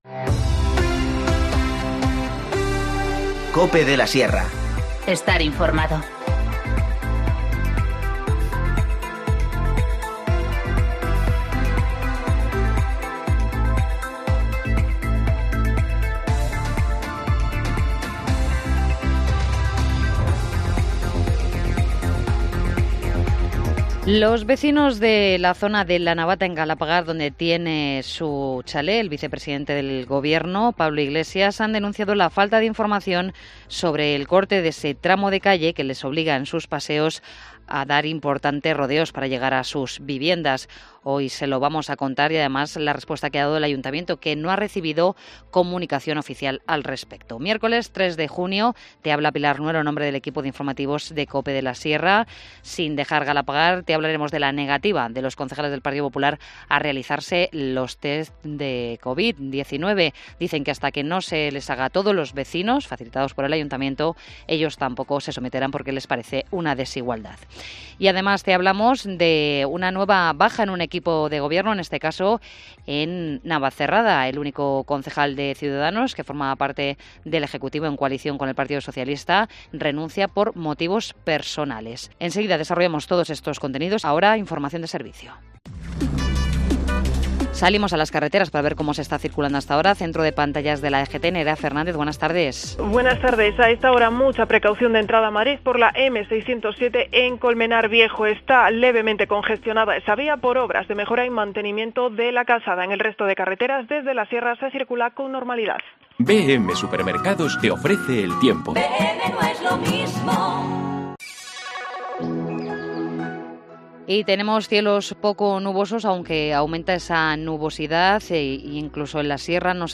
Informativo Mediodía 3 junio 14:20h